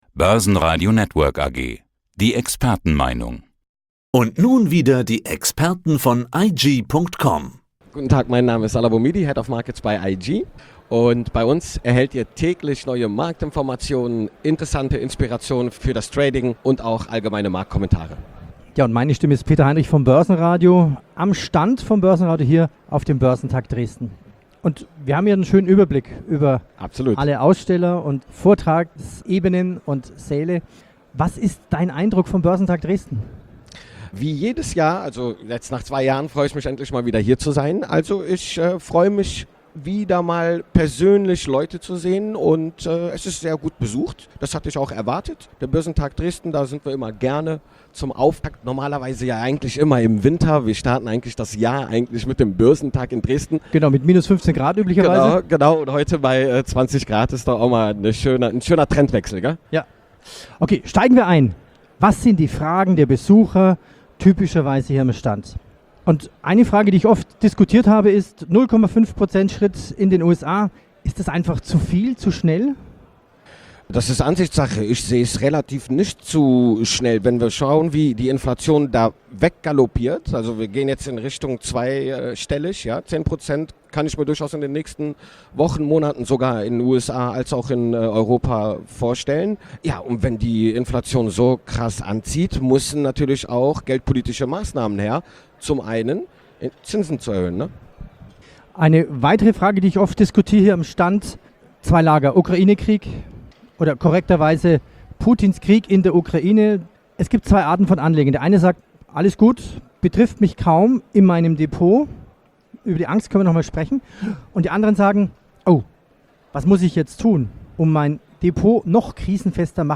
Auf dem Börsentag Dresden